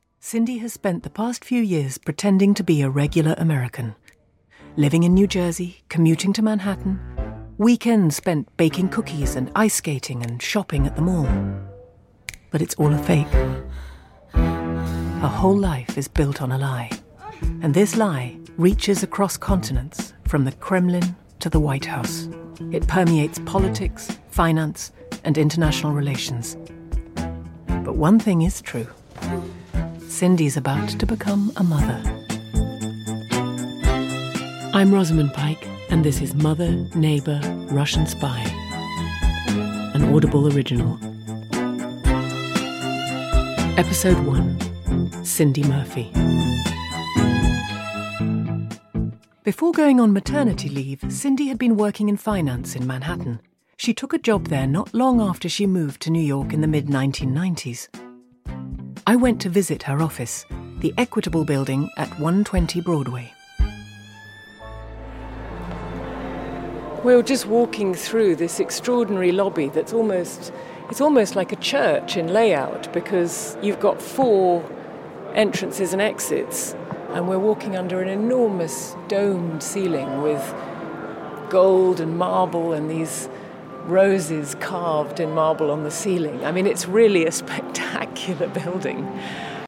Rosamund Pike. Voice artist represented by United Voices.
Rosamund-Pike-_Mother-Neighbor-Russian-Spy-Podcast_UV.mp3